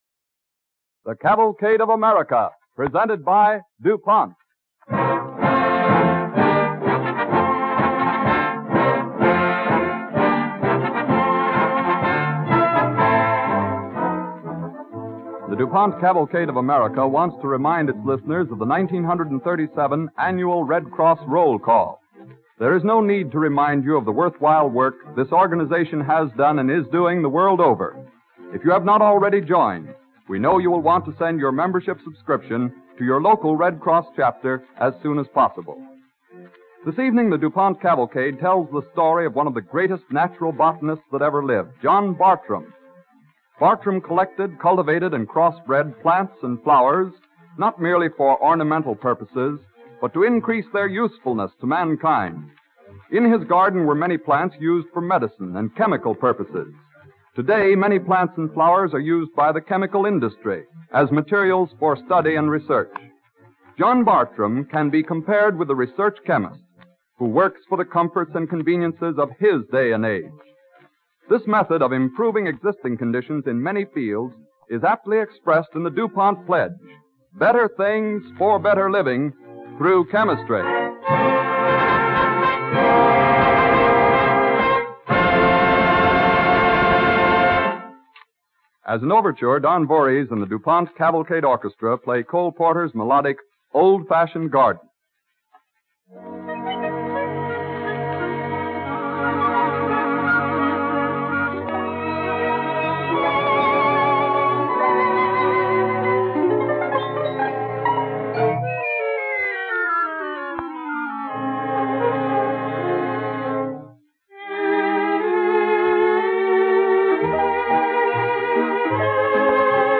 With announcer